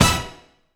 16BRASS01 -L.wav